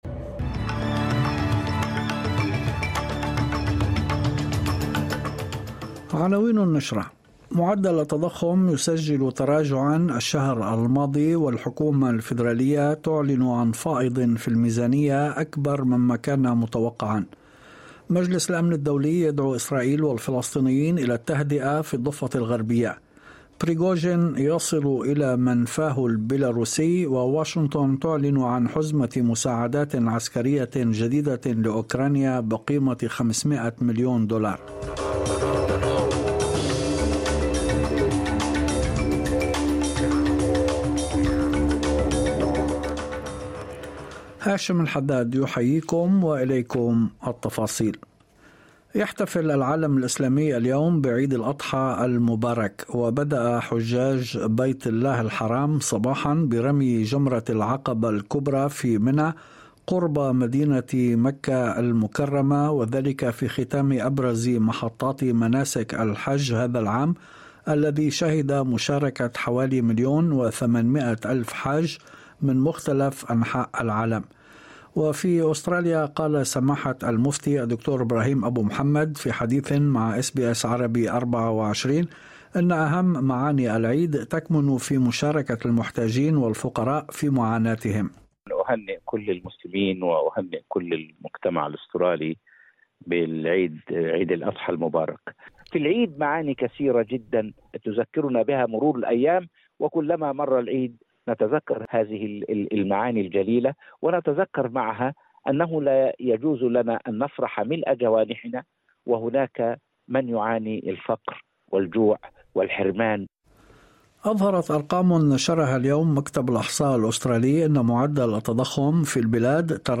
نشرة أخبار المساء 28/06/2023